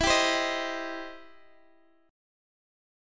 Listen to EmM7#5 strummed